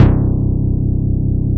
FURIOUS BASS.wav